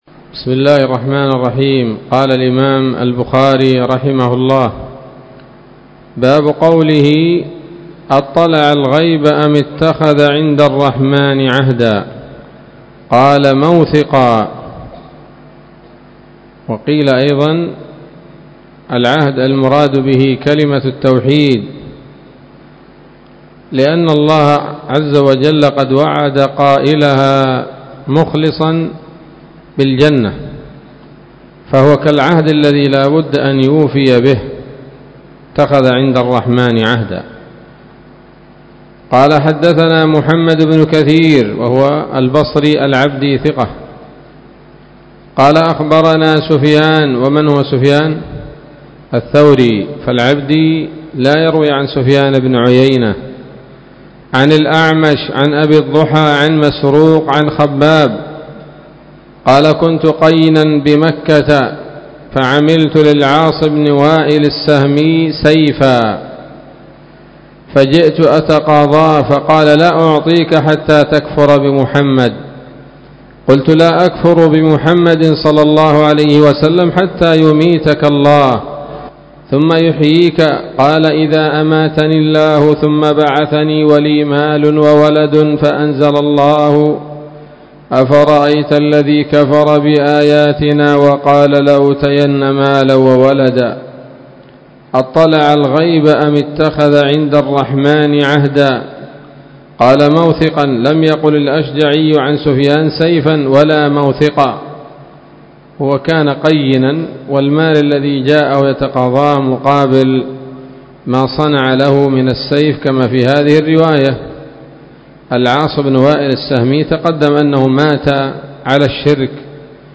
الدرس التاسع والستون بعد المائة من كتاب التفسير من صحيح الإمام البخاري